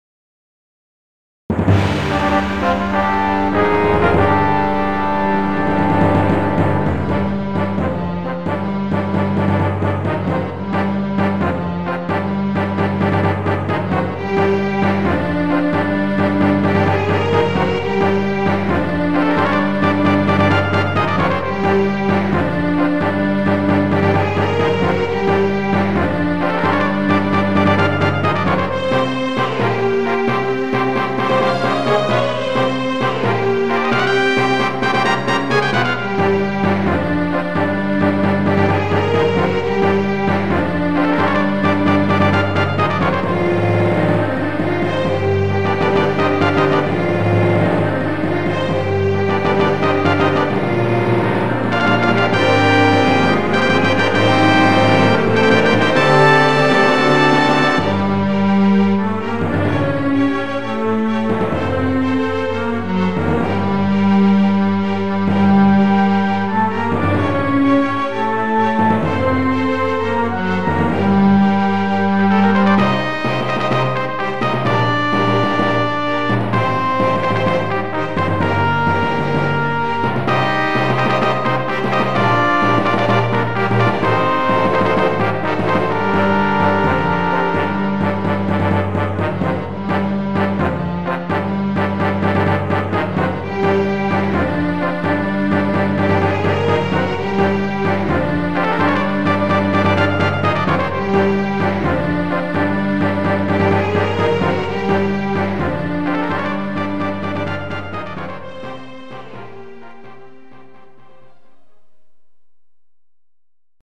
なんとも勇ましい雰囲気。GS音源。